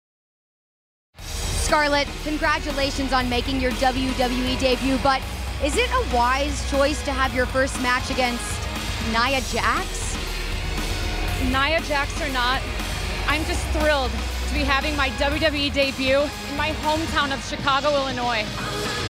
Yep, here she is being interviewed outside the ring prior to her initial outing in a WWE ring.
“LOCAL COMPETITOR” interview!